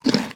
Minecraft Version Minecraft Version snapshot Latest Release | Latest Snapshot snapshot / assets / minecraft / sounds / mob / horse / eat2.ogg Compare With Compare With Latest Release | Latest Snapshot
eat2.ogg